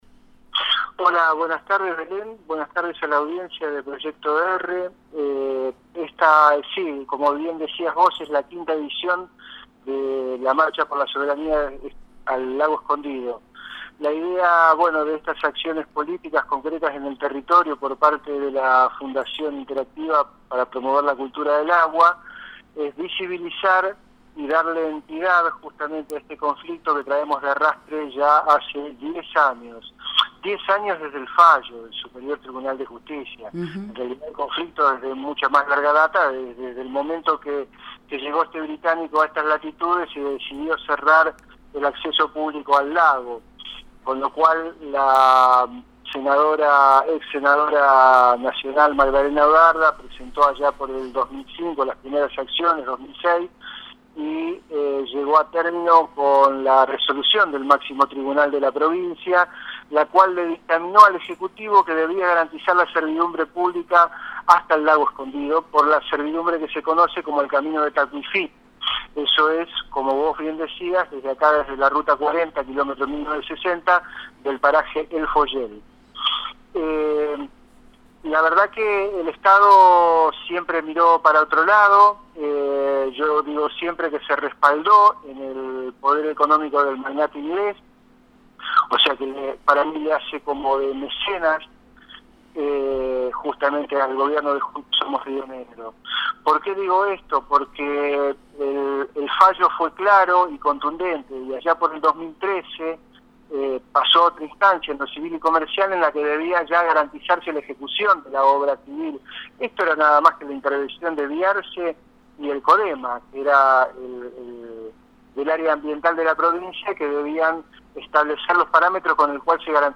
Escuchá el testimonio completo: https